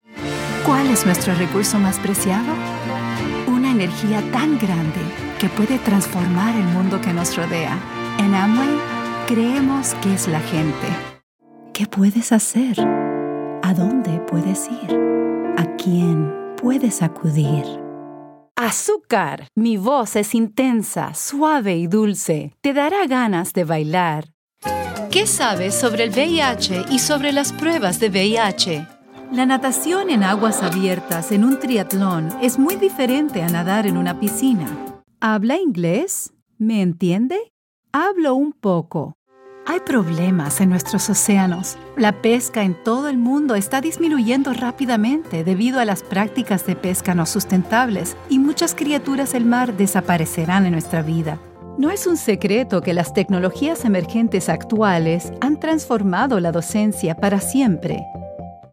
Narración
Mis clientes describen mejor mi voz como amigable, chispeante, expresiva, agradable, cálida y entusiasta.